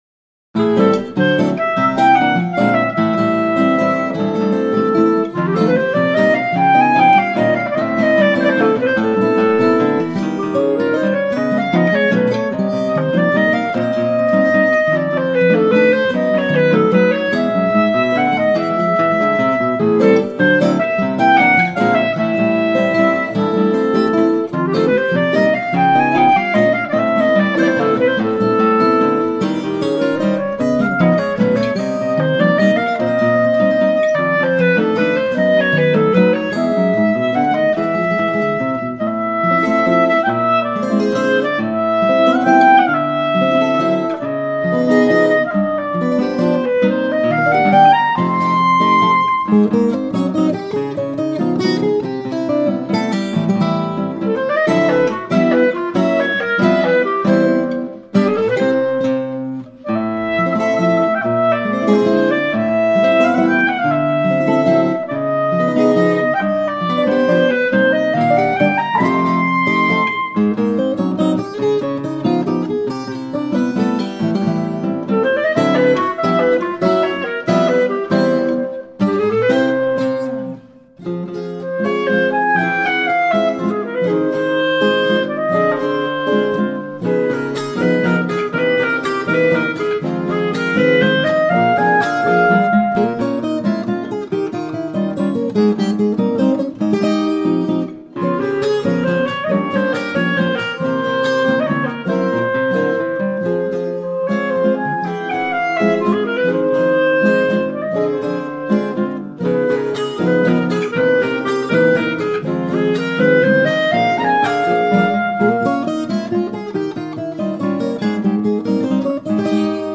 Pasillo